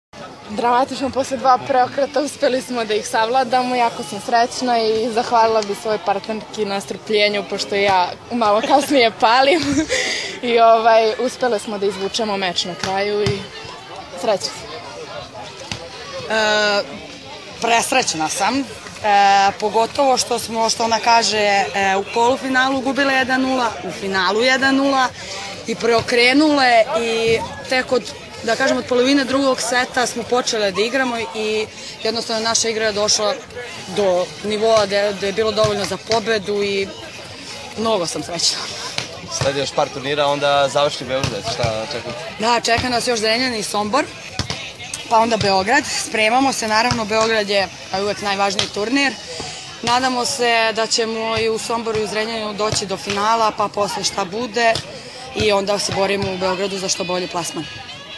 IZJAVE